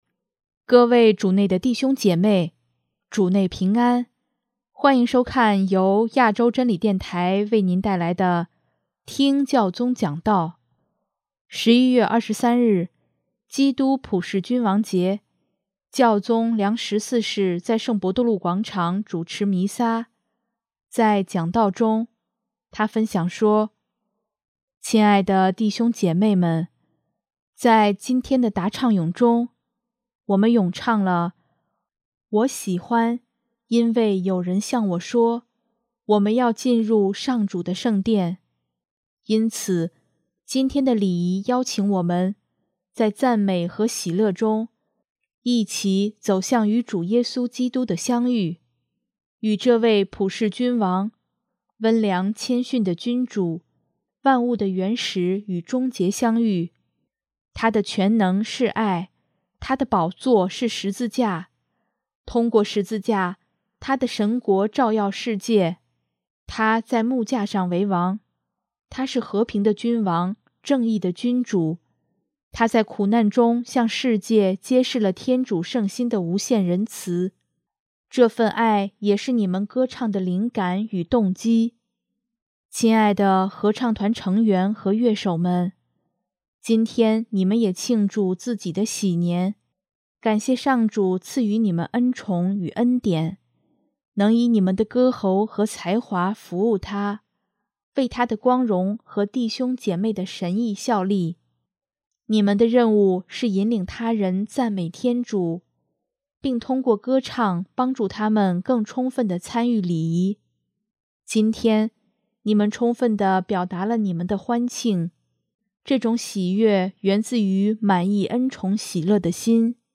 11月23日，基督普世君王节，教宗良十四世在圣伯多禄广场主持弥撒，在讲道中，他分享说：